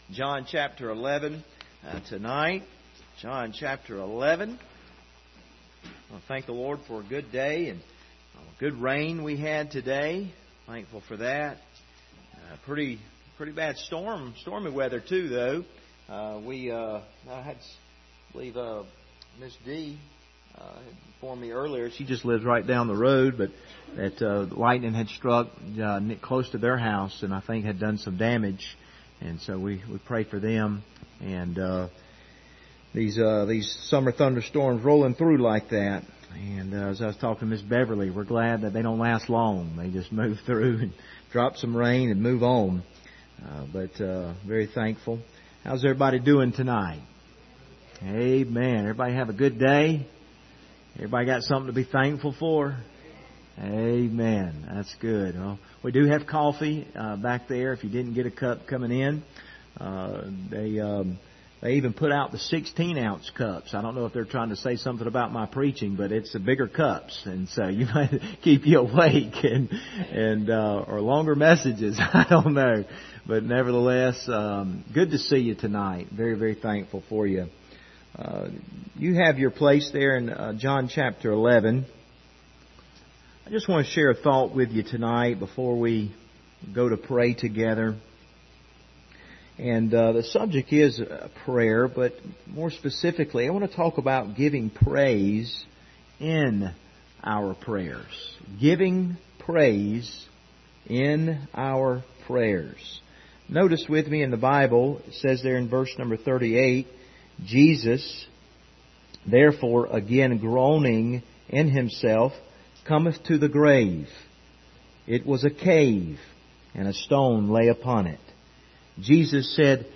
Passage: John 11:38-44 Service Type: Wednesday Evening